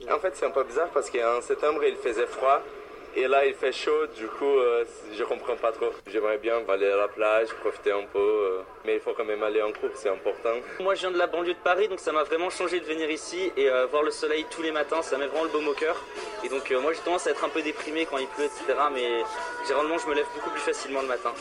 Ce matin, on pouvait entendre aux infos le témoignage de deux étudiants installés à Marseille depuis peu et qui apprécient cette belle arrière-saison.
Son français oral est parfait, dans un contexte ordinaire :
– Il emploie Du coup, très fréquent à l’oral.